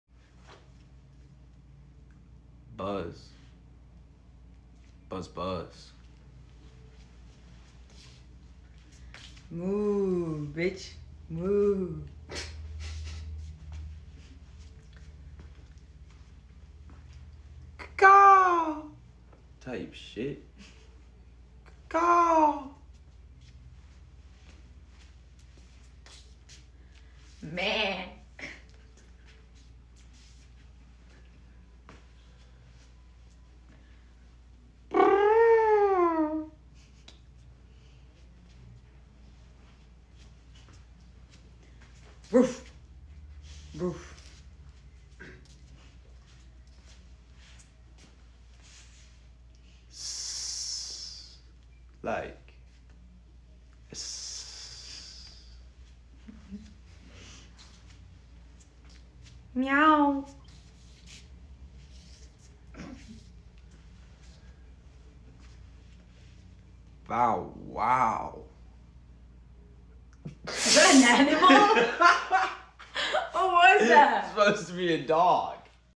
Animal noises but they’re nonchalant sound effects free download